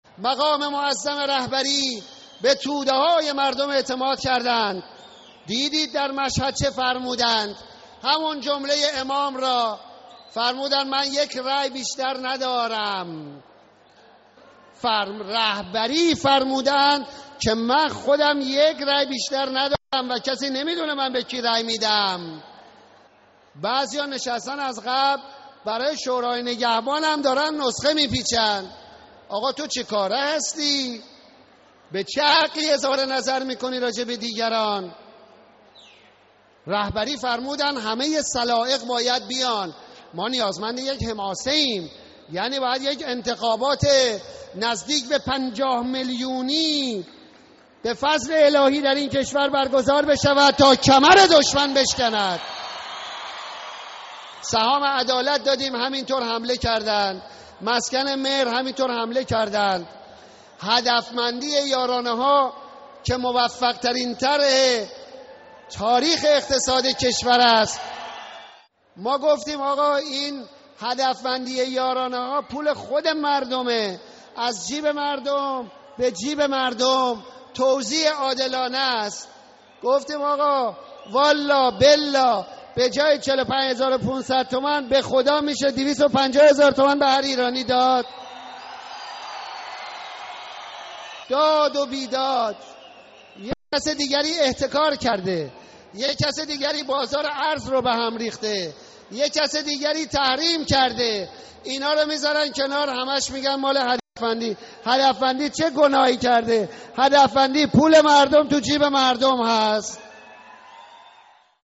گزیده‌ای از سخنرانی محمود احمدی‌نژاد در اهواز